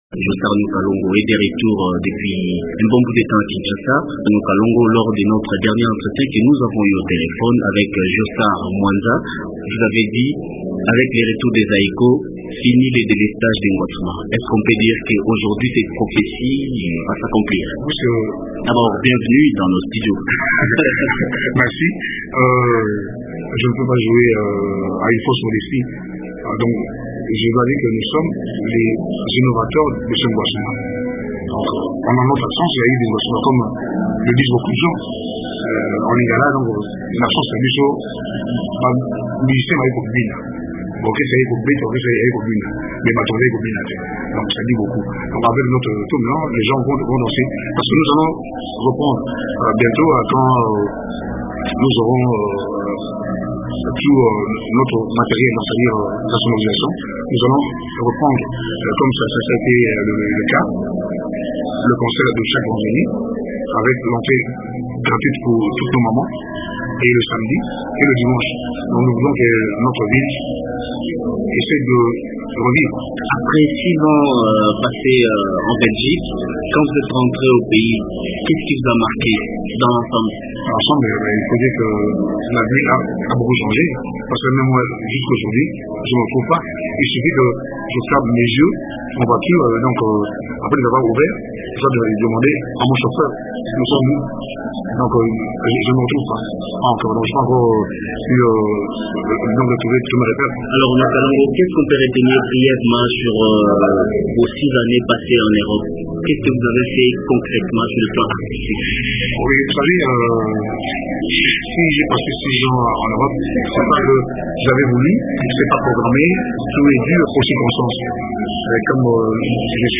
a surpris le groupe en pleine séance de répétition à l’Hôtel de la Funa